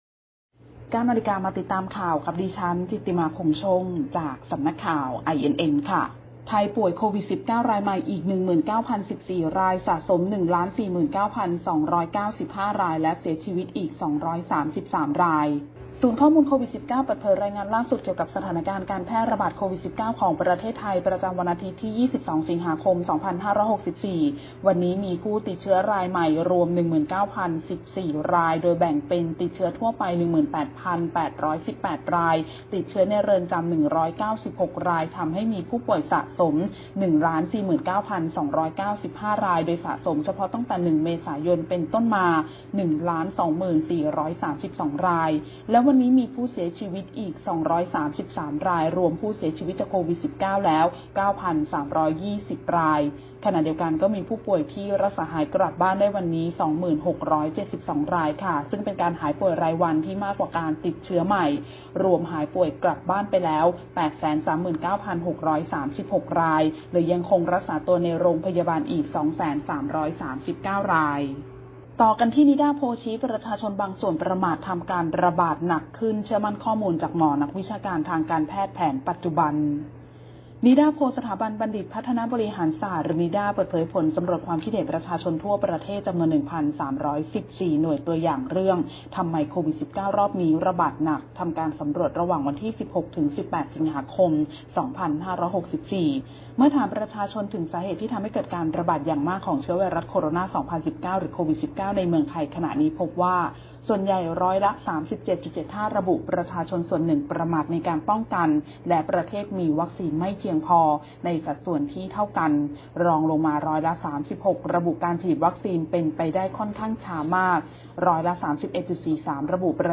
ข่าวต้นชั่วโมง 09.00 น.